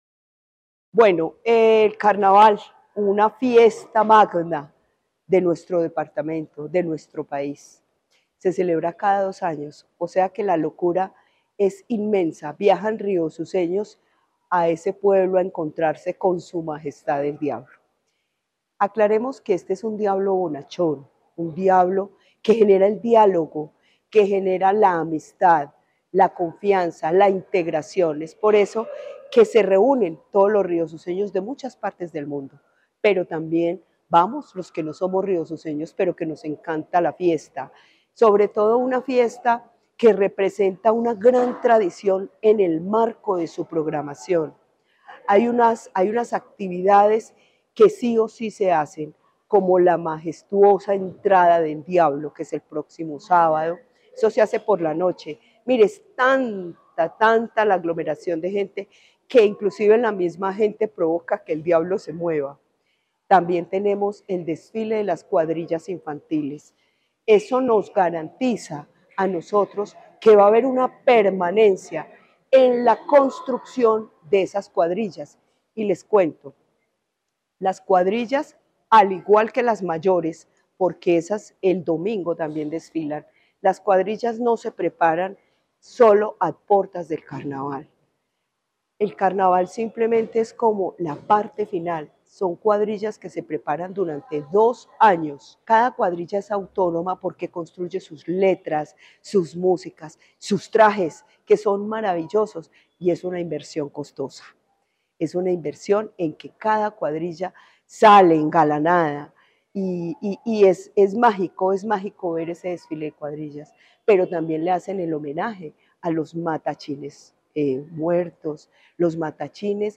Luz Elena Castaño Rendón, secretaria de Cultura de la Gobernación de Caldas.